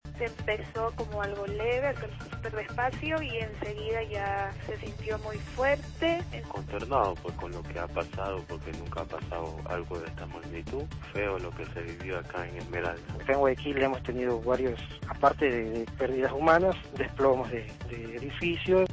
COPE habla con testigos del terremoto de Ecuador